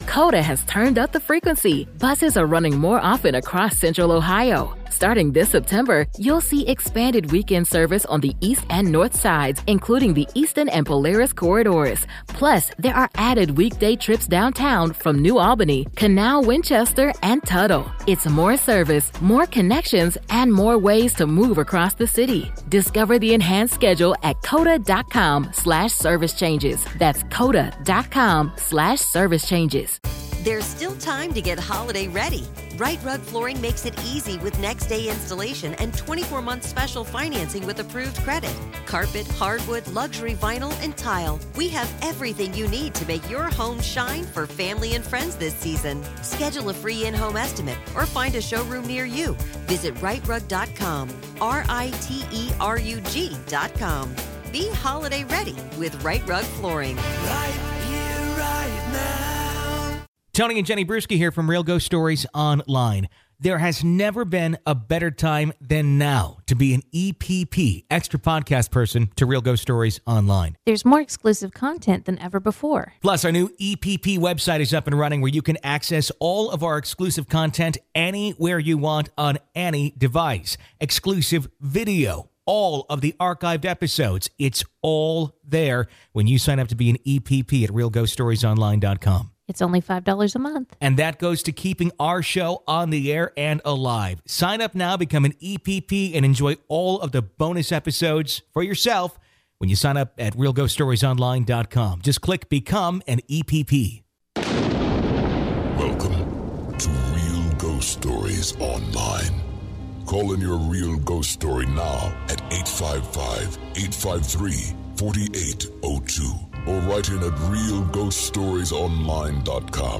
Today's episode focuses on the undead, or as you may call them "Ghosts". We take your calls and hear your real ghost stories.